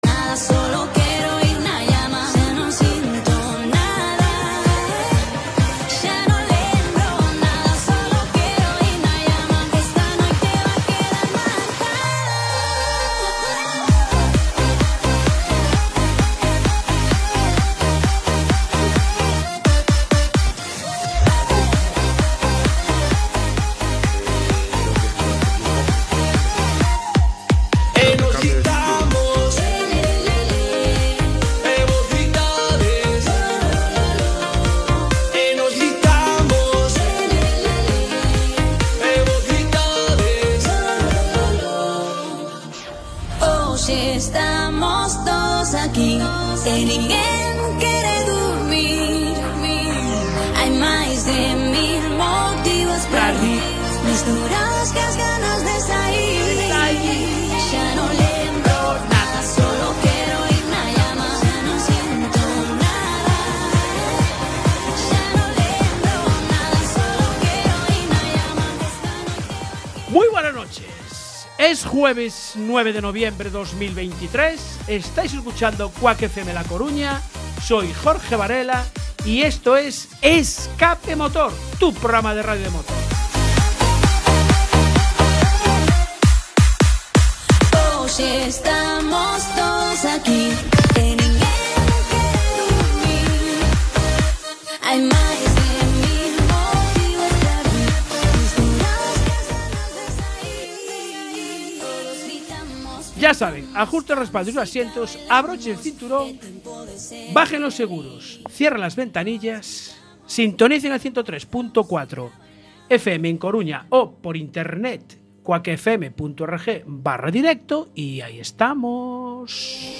escuchamos dos minutos de la narración de Lobato de las ultimas vueltas del mano a mano de Checo y Alonso que pasarán a la historia de la Fórmula Uno.